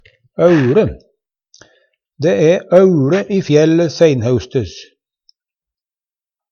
DIALEKTORD PÅ NORMERT NORSK auLe einsamt Ubunde han-/hokj.